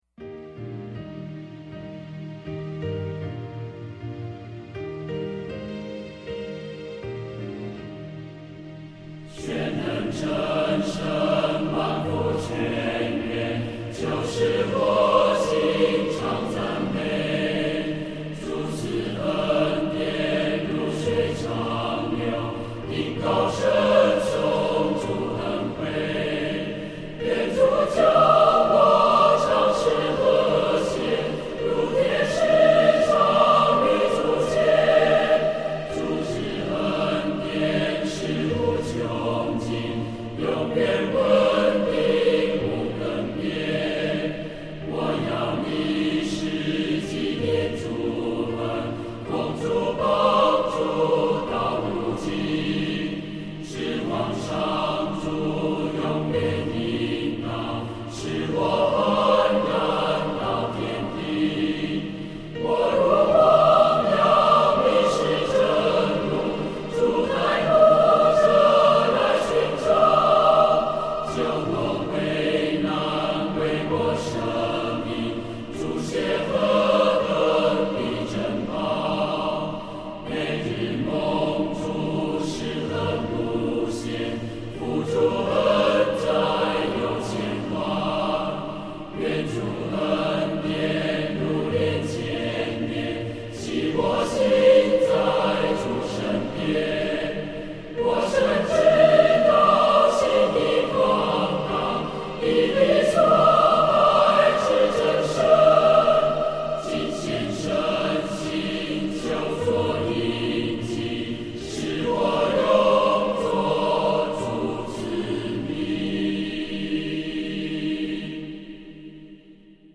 这首诗的曲调有很强烈的民族风味，全曲只有两句。